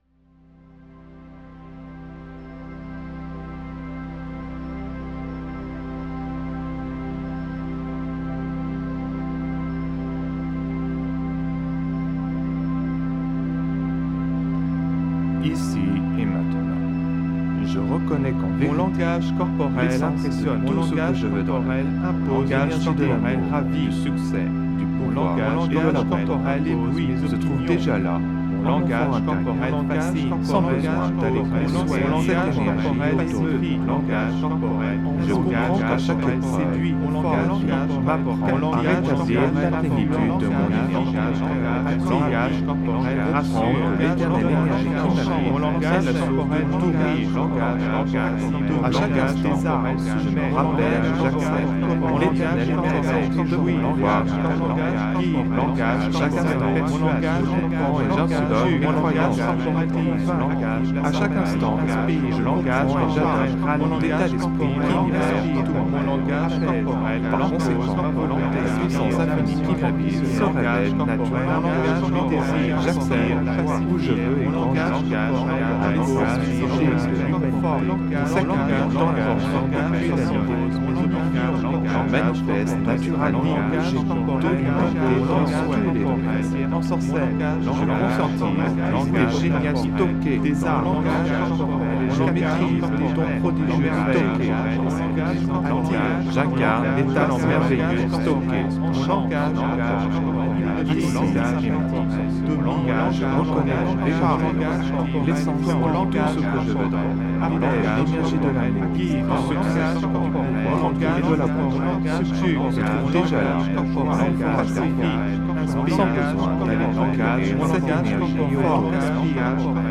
Ondes gamma musicales 96,82 Hz (DO 3ème octave 261,63 Hz et MI 2ème octave 164,81 Hz).
La voix se multiplie pour produire un effet 360° SUBLIMINAL très impactant sur l'esprit. Par subliminal, comprenez qu’il est difficile d’être conscient de toutes les voix en même temps.